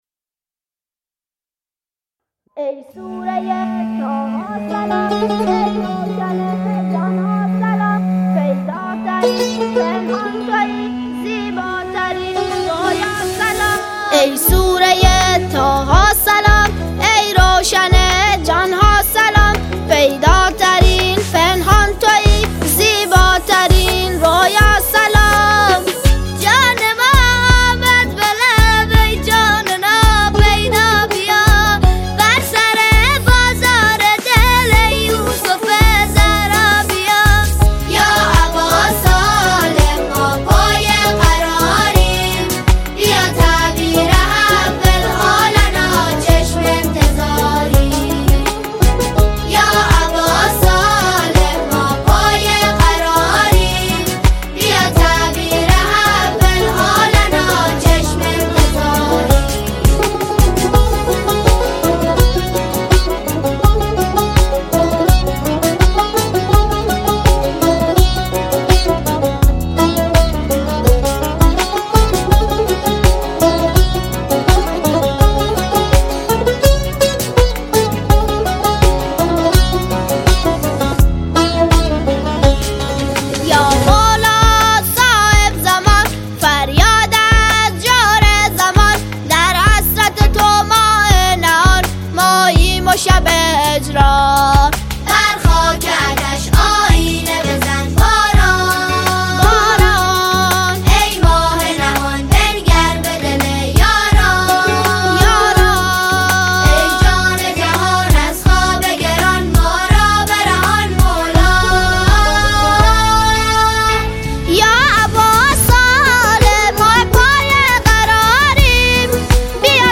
نجوایی آرام و دلنشین
سرود ، سرود مذهبی ، سرود مناسبتی